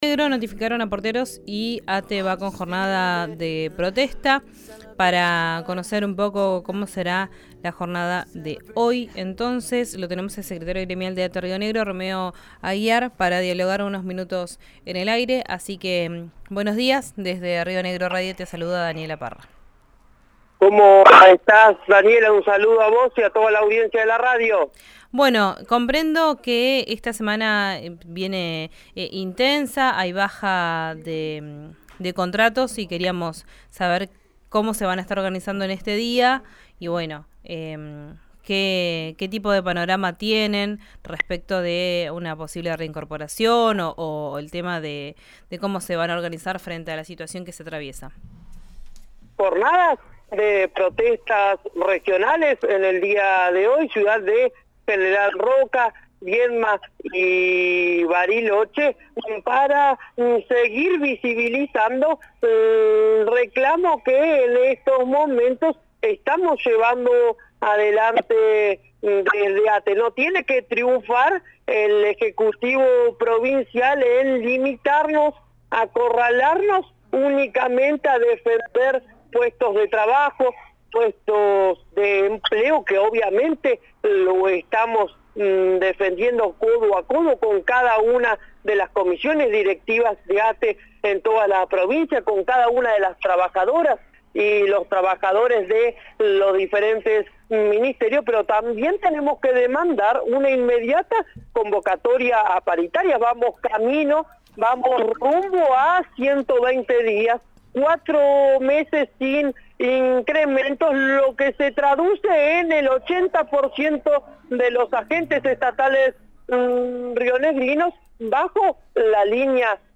en diálogo con RÍO NEGRO RADIO